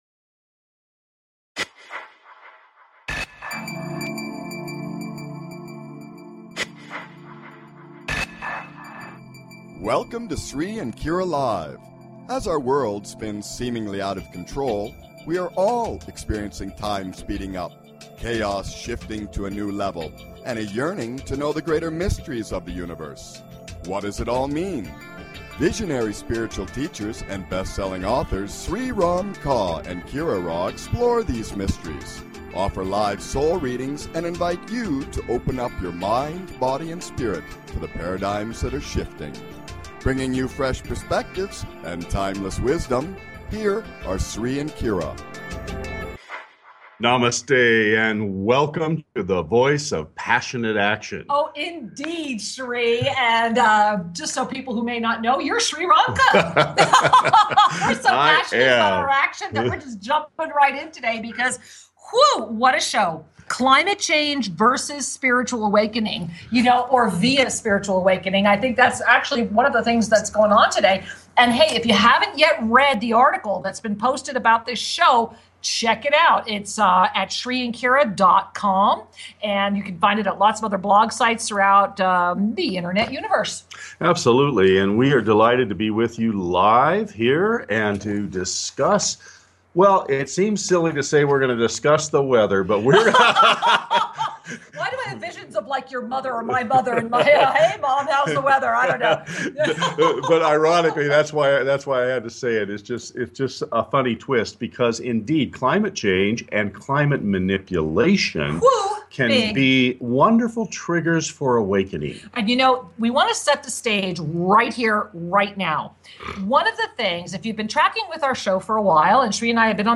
Talk Show Episode
Both true believers and climate change deniers are welcome and encouraged to listen and call in.